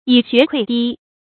蟻穴潰堤 注音： ㄧˇ ㄒㄩㄝˋ ㄎㄨㄟˋ ㄉㄧ 讀音讀法： 意思解釋： 小小的螞蟻窩，能夠使堤岸潰決。